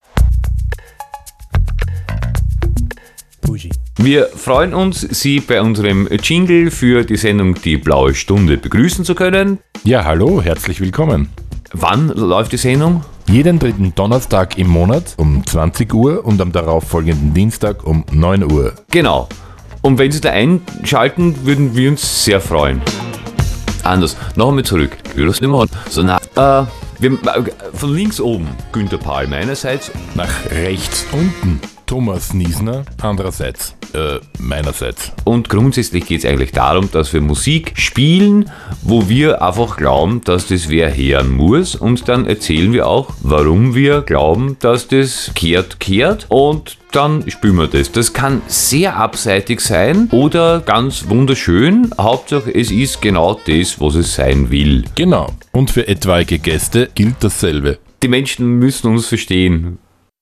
Sendungstrailer
FRS-TRAILER-BLAUESTUNDE-3-DONNERSTAG.mp3